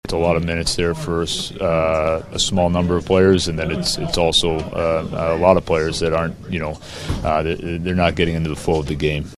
Muse says spending so much time killing penalties upset his line rotations and kept a lot of players on the bench.